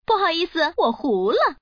Index of /guizhou_ceshi/update/1601/res/sfx/woman/